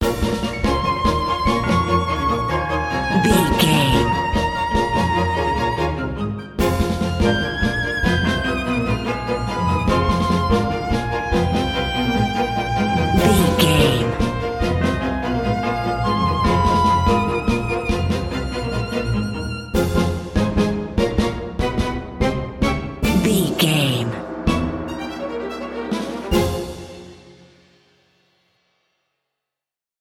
Ionian/Major
Fast
frantic
orchestral
violin
brass section
cello
piccolo
drums
driving
dramatic